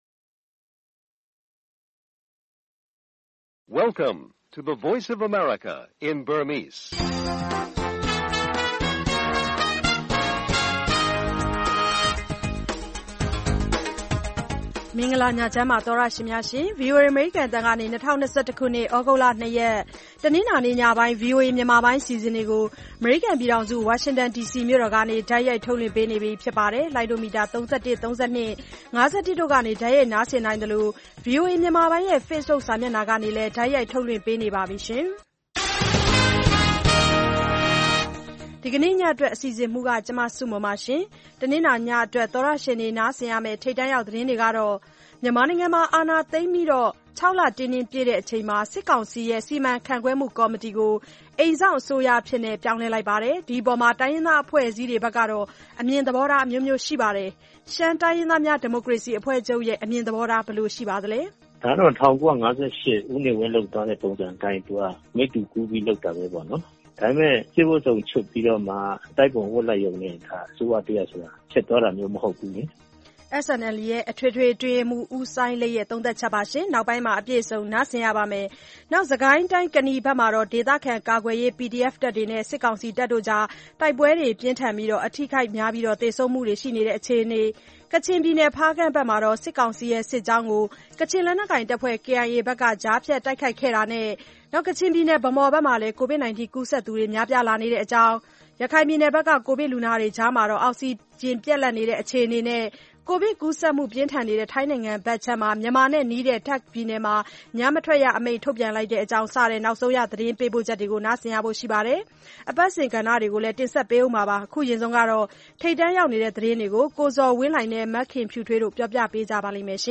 VOA ရေဒီယိုညပိုင်း ၉း၀၀-၁၀း၀၀ တိုက်ရိုက်ထုတ်လွှင့်မှု(သြဂုတ် ၂၊ ၂၀၂၁)